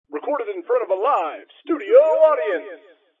Download Live Audience sound effect for free.
Live Audience